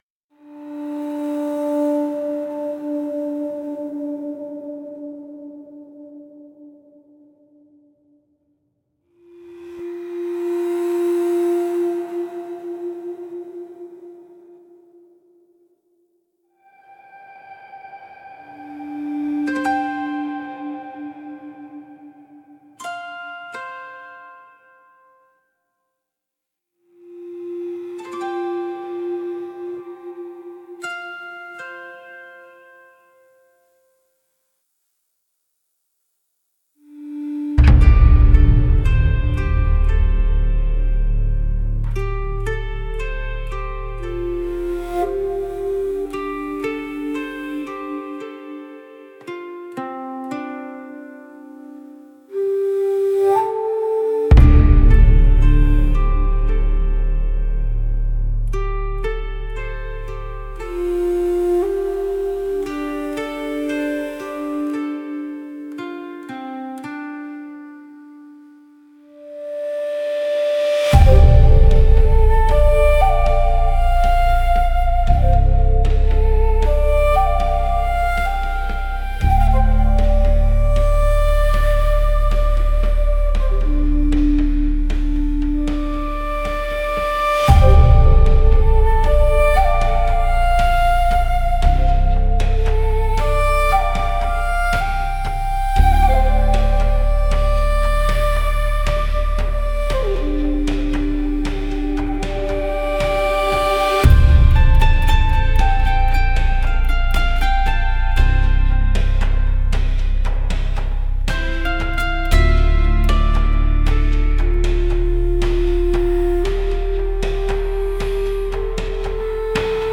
静かな序盤から、徐々に心地よいリズムが加わっていく展開は、夢の奥深くへと歩みを進める高揚感を演出します。
• ジャンル： エスニック・アンビエント / ワールドミュージック / 和モダン
• 雰囲気： ミステリアス / 幻想的 / 孤独 / 深淵 / 美しい